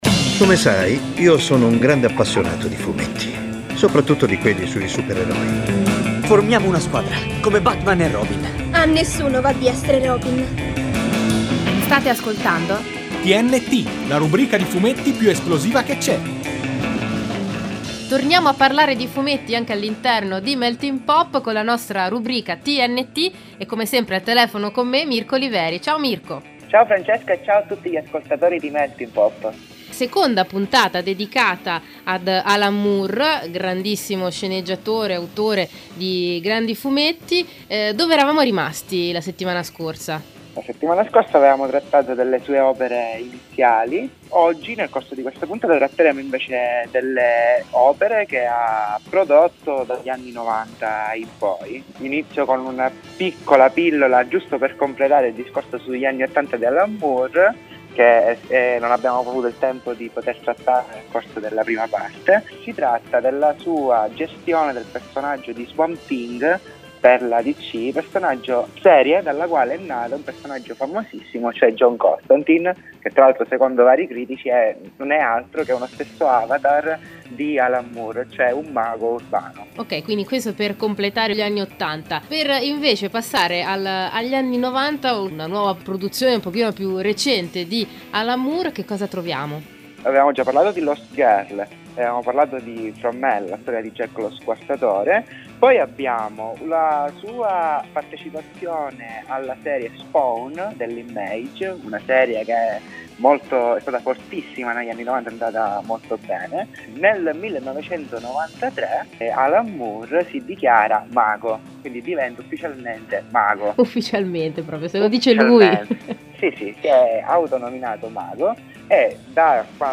Lo Spazio Bianco è lieto di presentarvi il podcast di TNT, la trasmissione radiofonica in onda tutti i venerdì su Contatto Radio. Seconda parte di una doppia puntata dedicata ad Alan Moore.